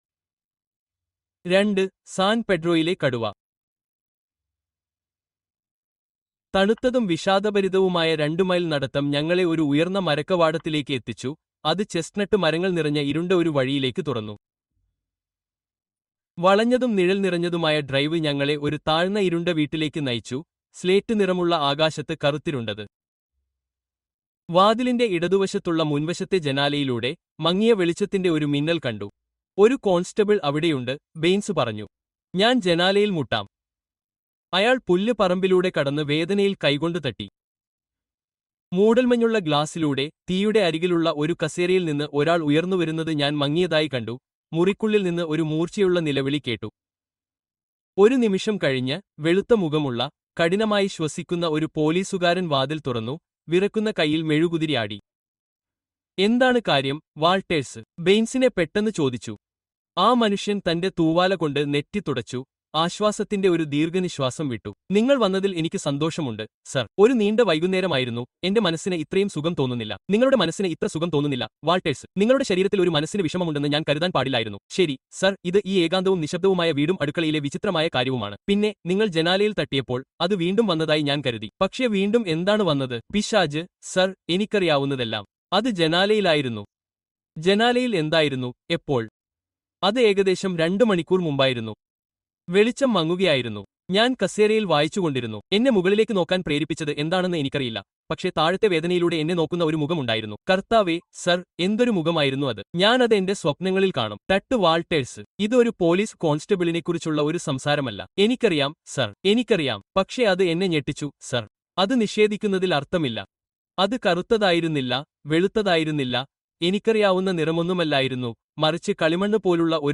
A Christmas Carol by Charles Dickens - Full Audiobook to Lift Your Spirits